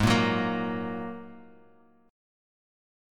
G#7sus2 chord